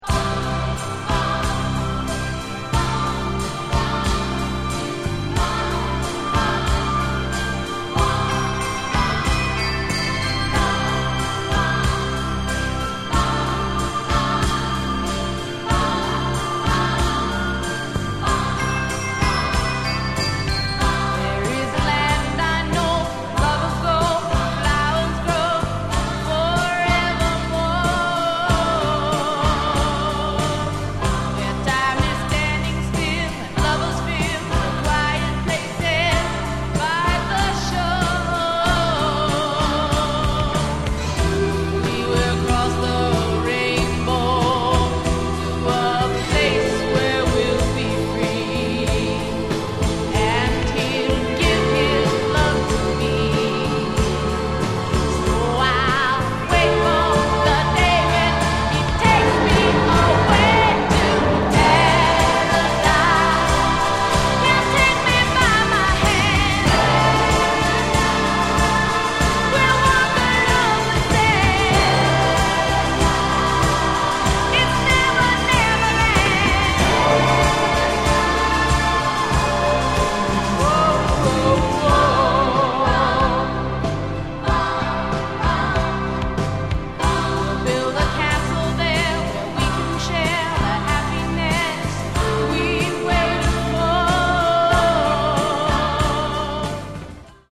Genre: Girl Group
This is a gorgeous, faithful cover